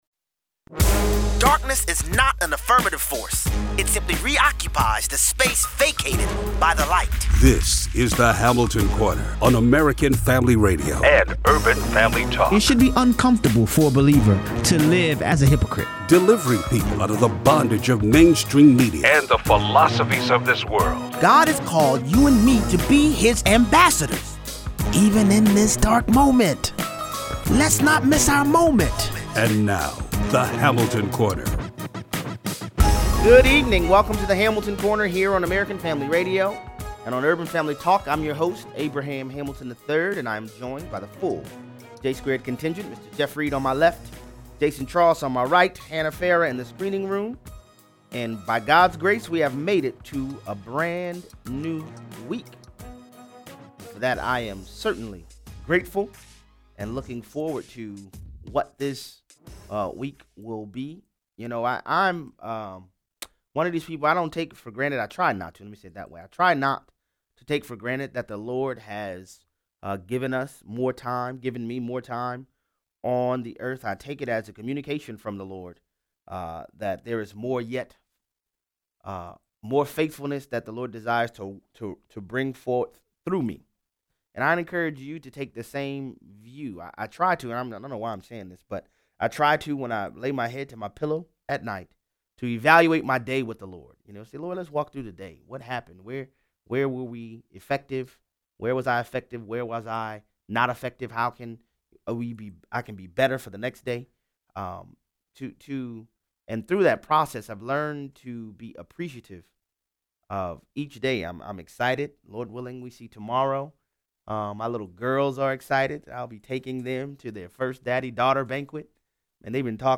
Stand firmly on the unchanging word of God. 0:18 - 0:35: The Russian investigation revealed there is a silent coup effort to overturn the will of the American people. The passage of H.J. Res. 31 shows the dual party establishment is also working to overturn the will of the American people. 0:38 - 0:55: Why did President Trump accept the Republicans advice and sign another terrible spending bill? Callers weigh in.